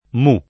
mi [mi+] s. m. (lettera greca Μ, μ) — es.: un mi maiuscolo [um m& mmaL2Skolo] — meno bene mu [